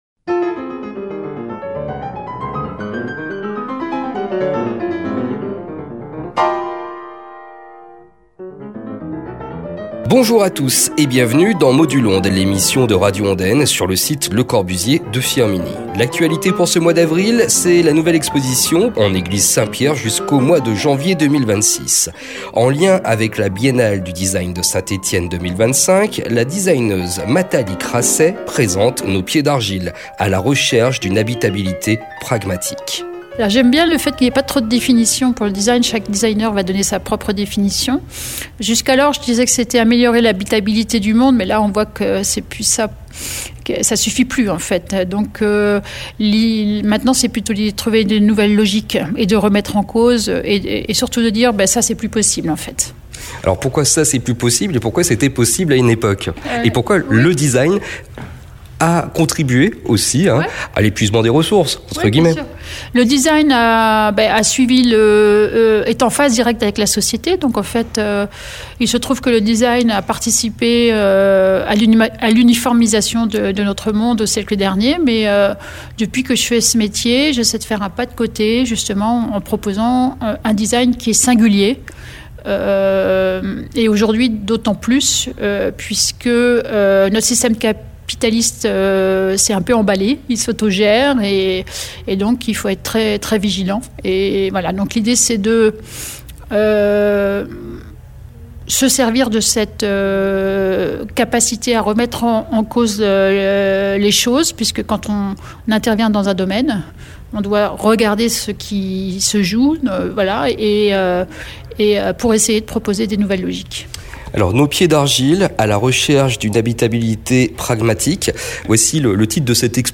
MODUL’ONDES, NOS PIEDS D’ARGILE, rencontre avec Matali Crasset.